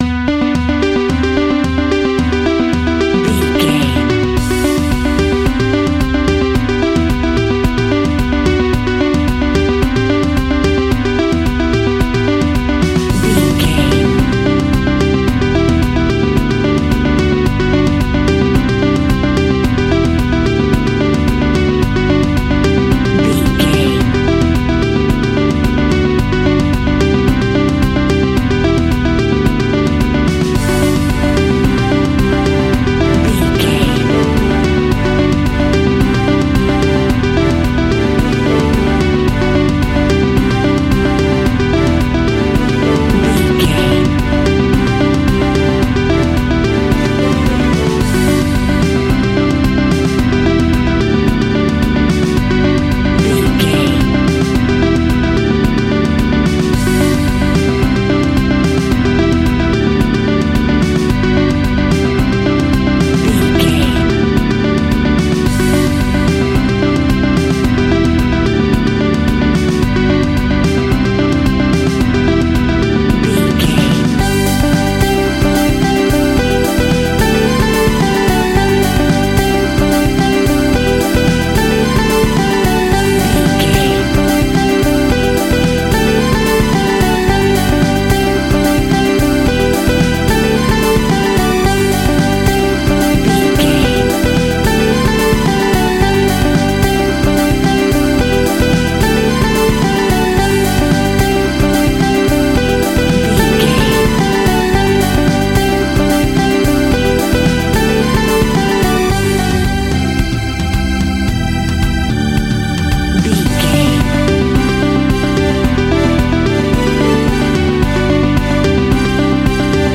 Indie Pop Rock from the 90s.
Ionian/Major
energetic
uplifting
upbeat
groovy
guitars
bass
drums
piano
organ